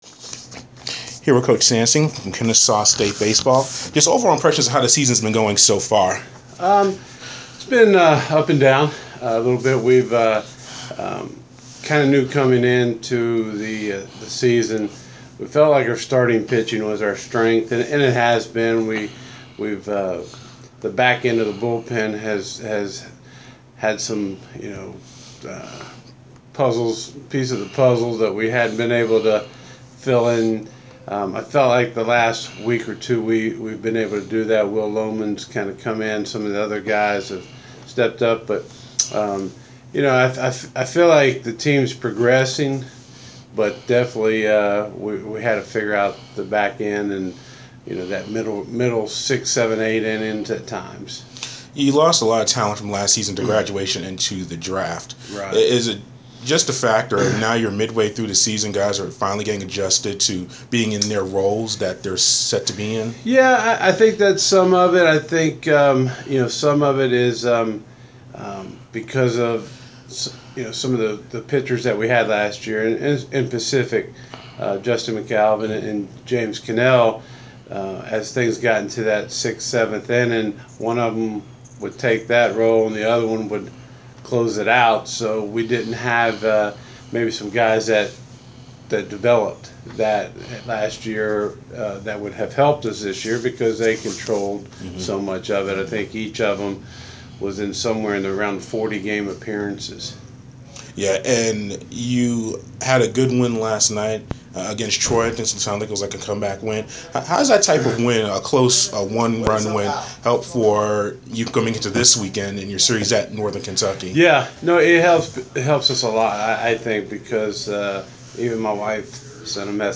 Inside the Inquirer: Exclusive interview